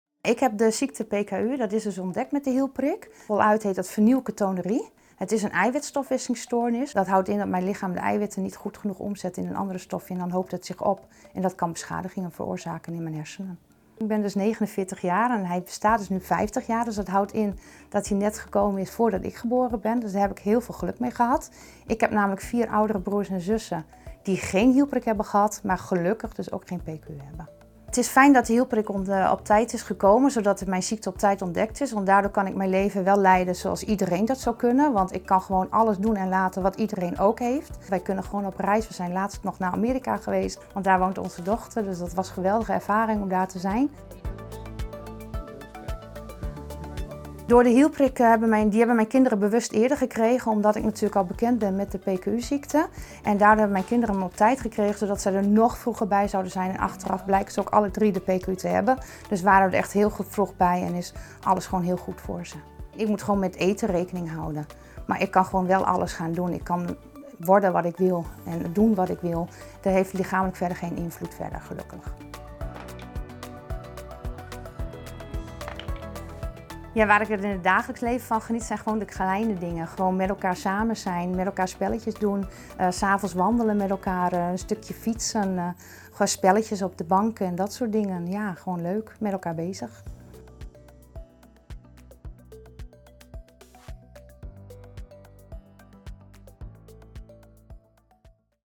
In deze video vertelt zij wat PKU is en hoe het is om met deze stofwisselingsziekte te leven.